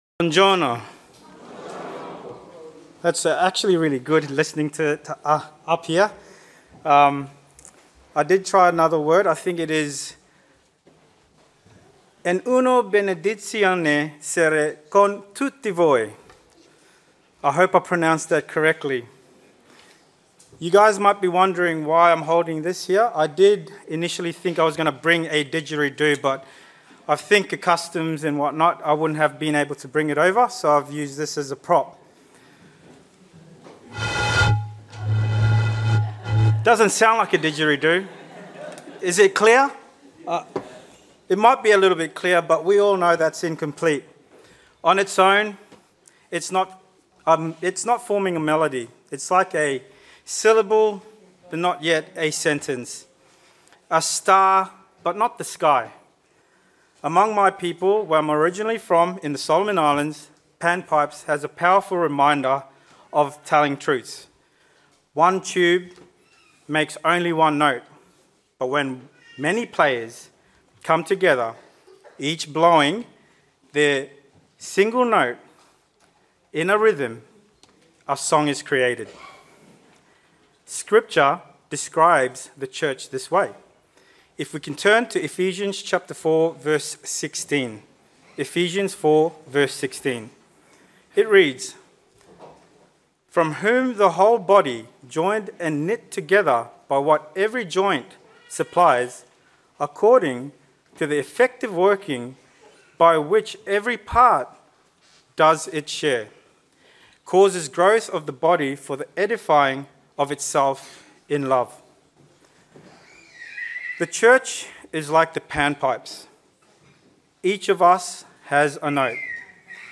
Sermons
FoT 2025 Sabaudia (Italy): 7th day